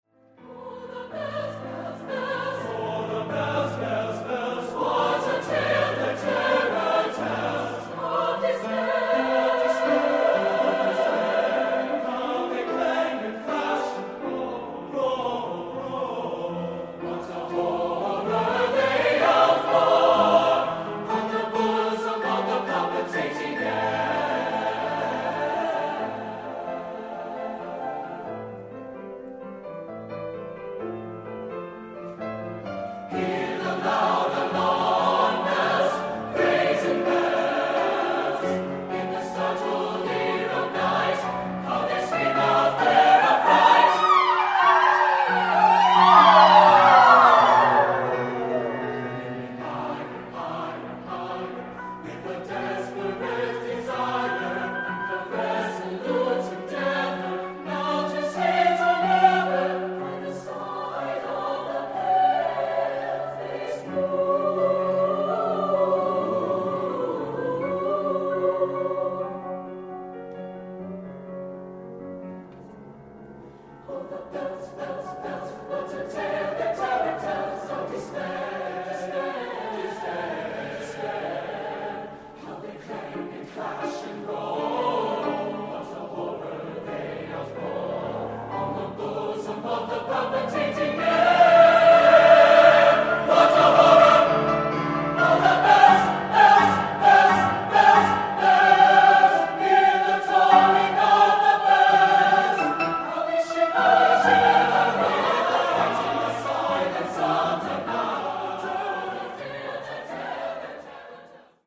Choeur Mixte (SATB) et Piano